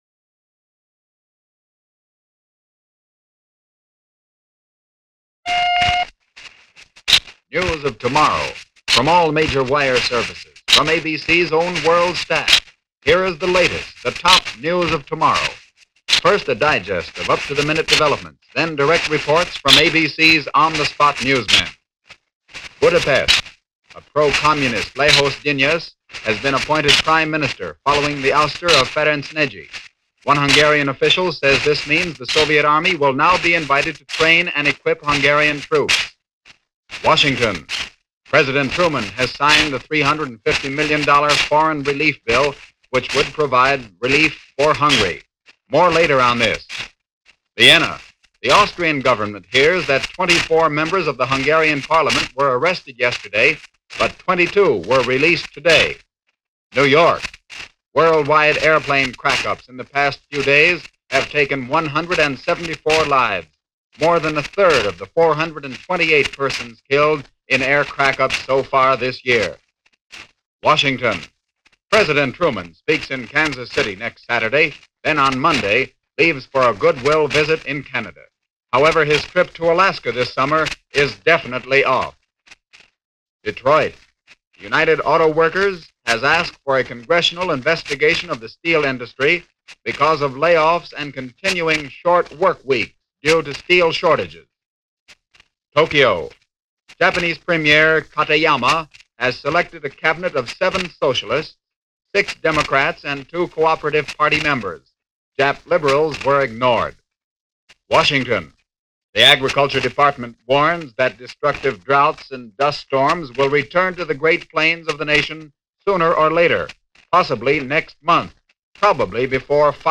Hungary: No Miracle. No Wonder. Just Drama - May 31, 1947 - ABC Radio Network News Of Tomorrow May 31, 1947